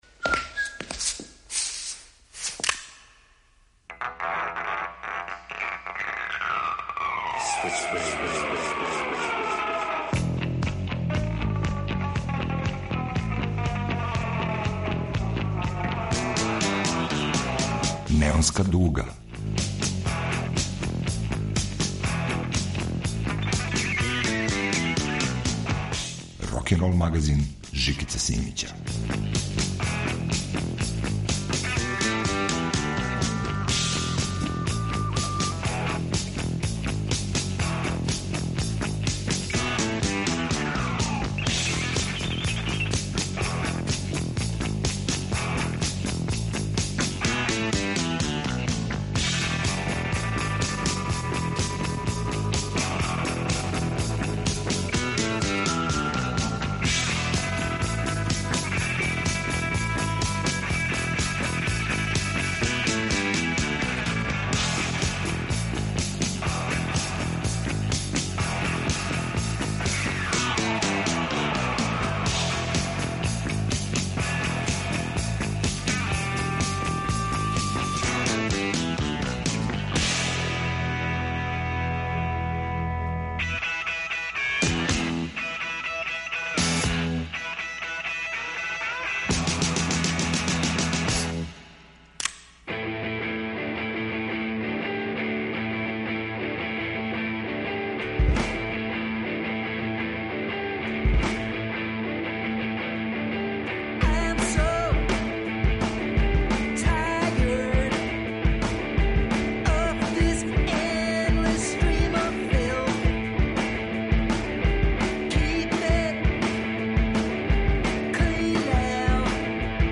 Има ли рок музике без електричне струје? Неонска дуга трага за одговором. 18 опасних, електрифицираних песама је на репертоару.
Вратоломни сурф кроз време и жанрове.